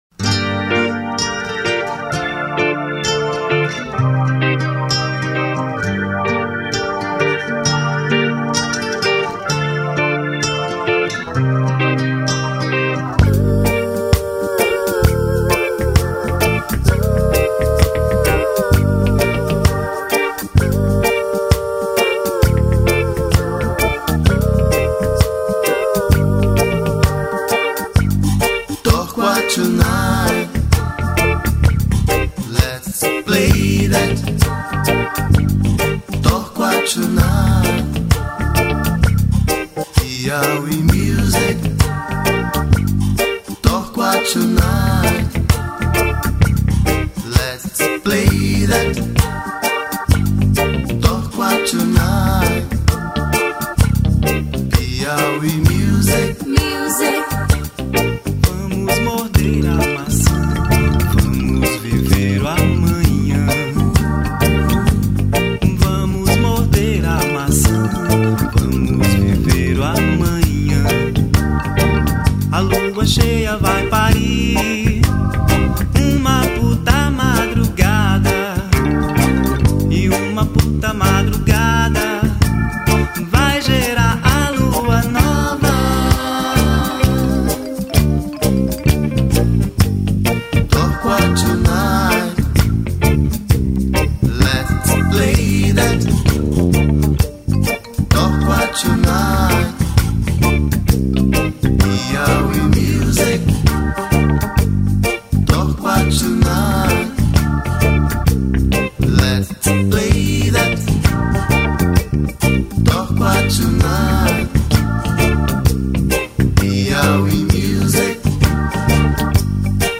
2962   04:09:00   Faixa: 5    Rock Nacional